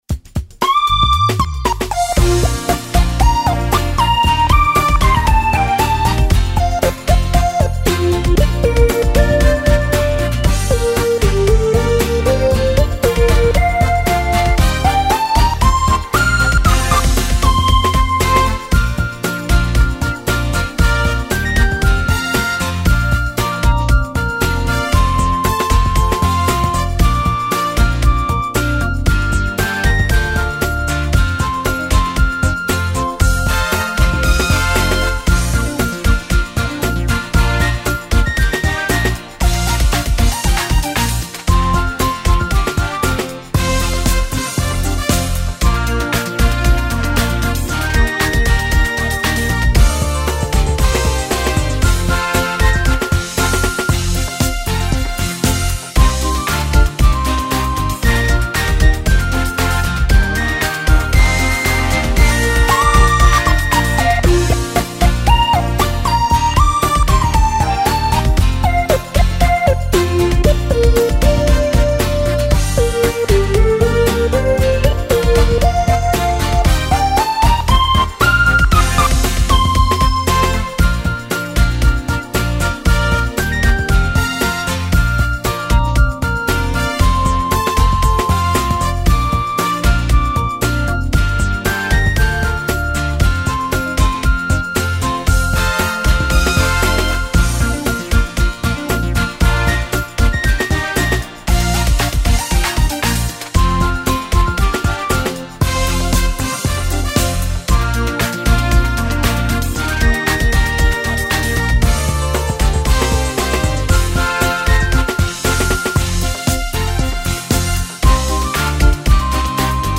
沙鼻牯爸爸(純伴奏版) | 新北市客家文化典藏資料庫